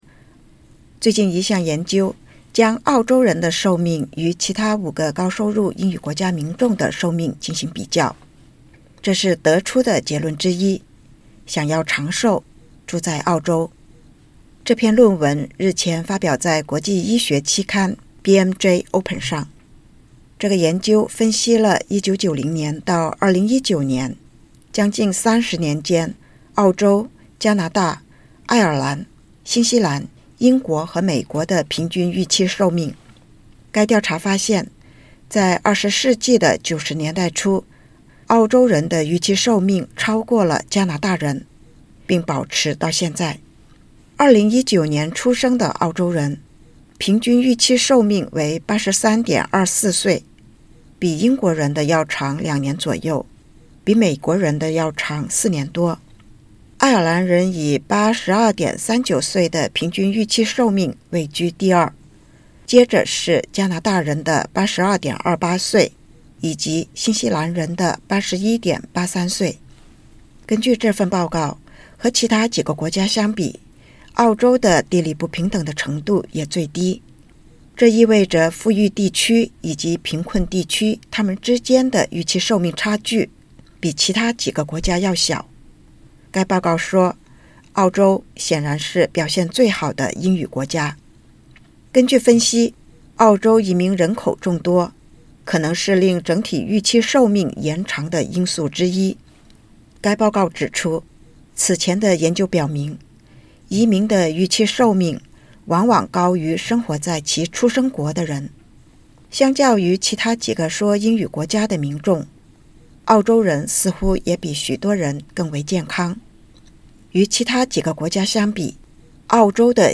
根据新近一项研究，澳大利亚人比其他多个英语国家同龄人的预期寿命要更长。请点击 ▶ 收听报道。